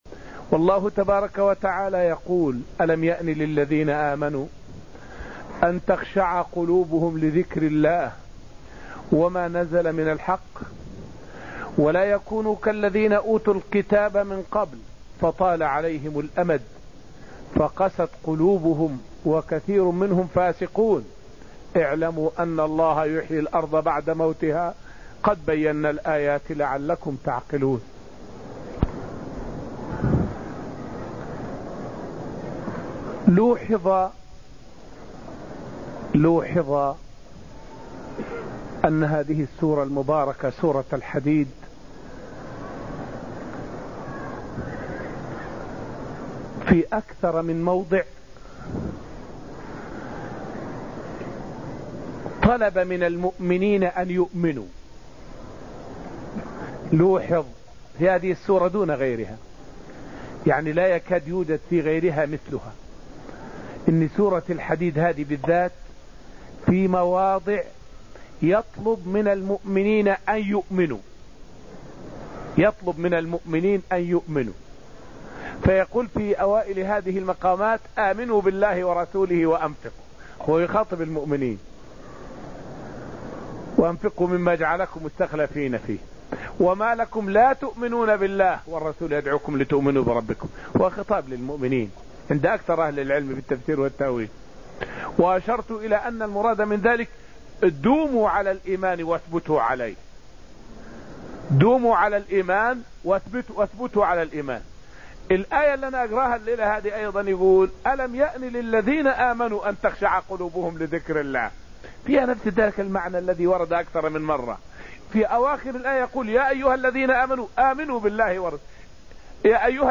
فائدة من الدرس السابع عشر من دروس تفسير سورة الحديد والتي ألقيت في المسجد النبوي الشريف حول خطاب الله للمؤمنين في سورة الحديد.